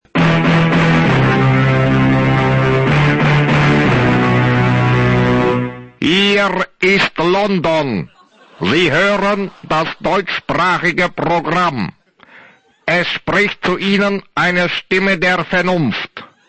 Da mich Einige gefragt haben, habe ich hier mal den Sound des UT1